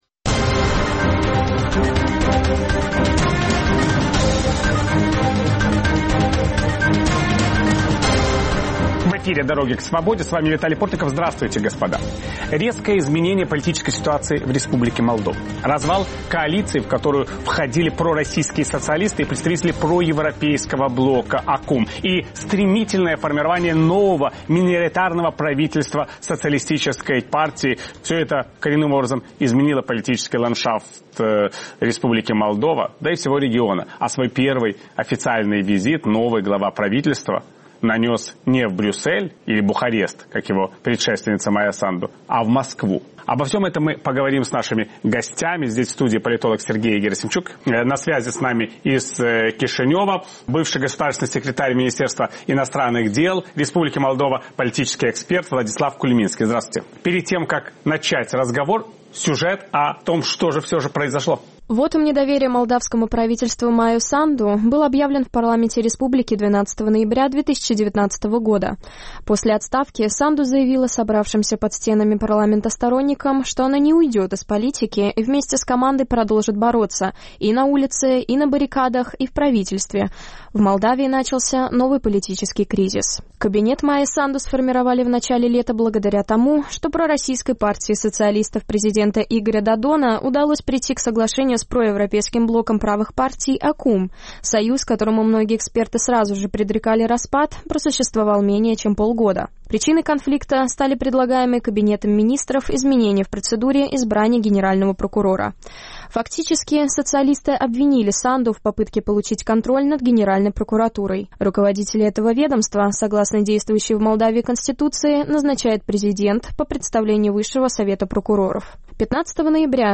В чем причины развала коалиции пророссийских и проевропейских сил? Как будет развиваться ситуация в Республике Молдова после смены власти? Виталий Портников беседует с политологом